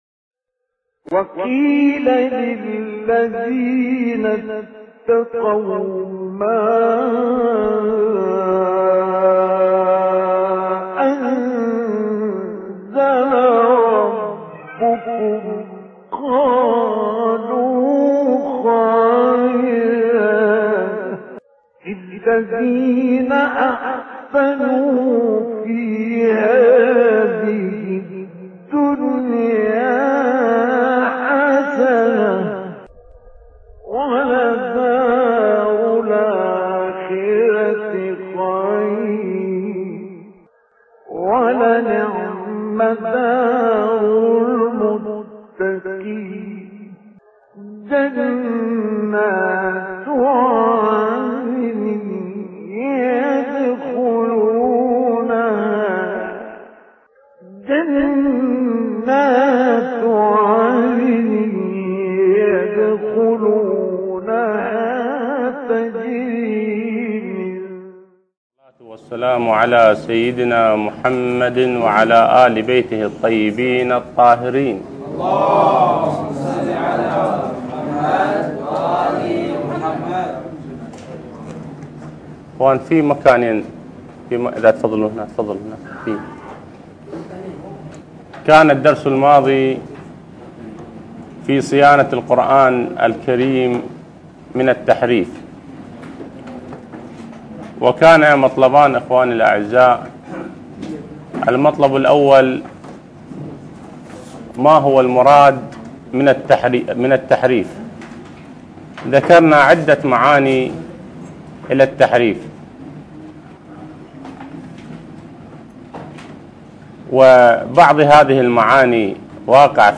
الدرس السادس اعجاز القرآن - لحفظ الملف في مجلد خاص اضغط بالزر الأيمن هنا ثم اختر (حفظ الهدف باسم - Save Target As) واختر المكان المناسب